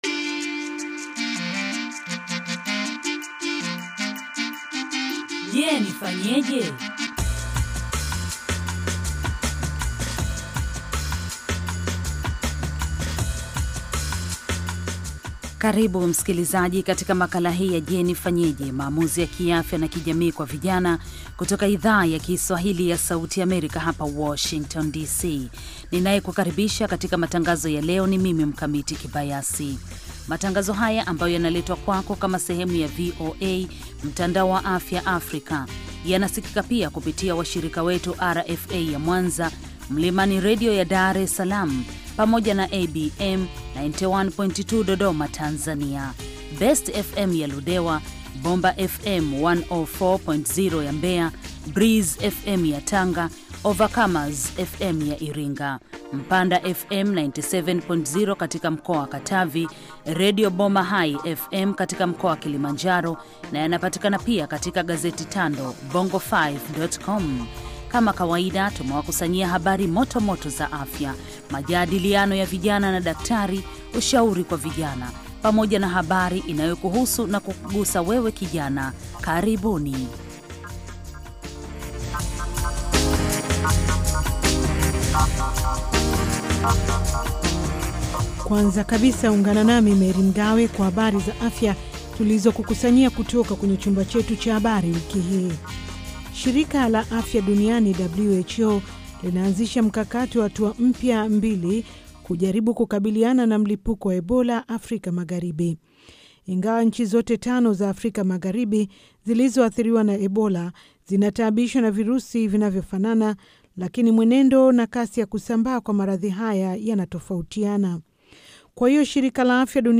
Je Nifanyeje? Maamuzi ya Afya na Kijamii kwa Vijana - Kipindi cha dakika 30 kinacholenga vijana kwa kufuatulia maisha ya vijana, hasa wasichana, kuwasaidia kufanya maamuzi mazuri ya kiafya na kijamii ambayo yanaweza kuwa na maana katika maisha yao milele. Kipindi hiki kina sehemu ya habari za afya, majadiliano, na maswali na majibu kwa madaktari na wataalam wengine.